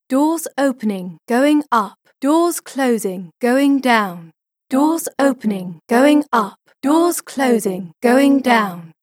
The voice is being mixed with a pitch-shifted version of itself.
Mix the original voice with an autotuned version.